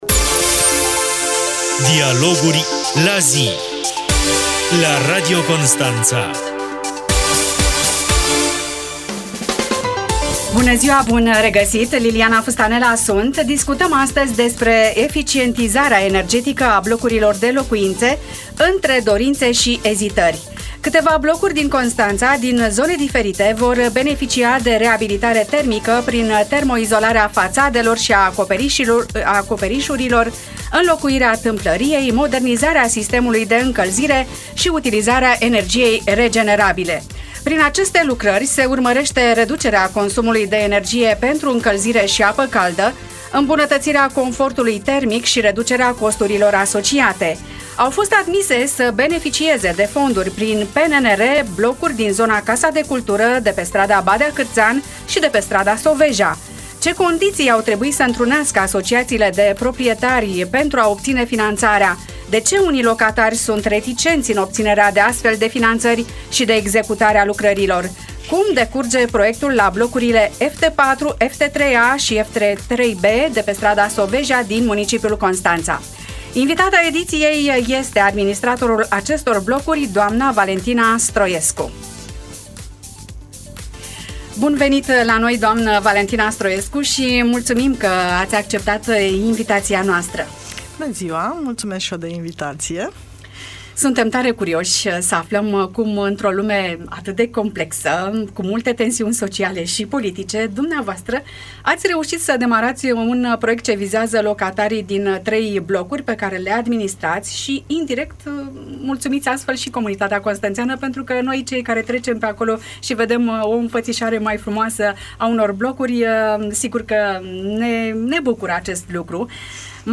Pe strada Soveja lucrările sunt mai avansate – au început în mai – la 3 blocuri care au primit aviz pentru proiectul finanțat cu aproape 25 milioane de lei, tot prin PNNR. Ce condiții trebuie să îndeplinească asociațiile de proprietari pentru a obține finanțarea și de unde reticența unor proprietari față de astfel de proiecte aflăm din emisiunea „Dialoguri la zi”.